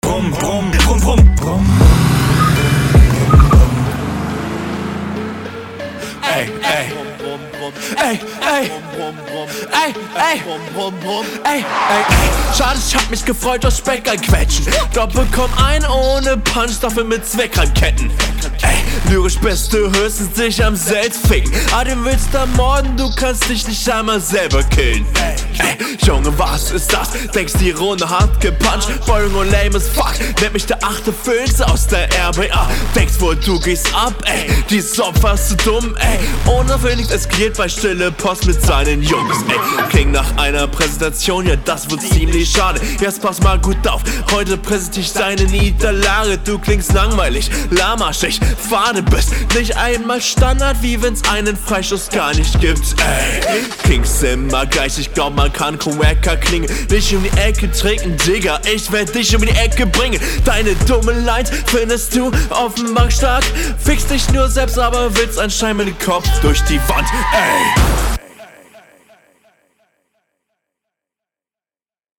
HÖR AUF ZU NUSCHELN, aber gute steigerung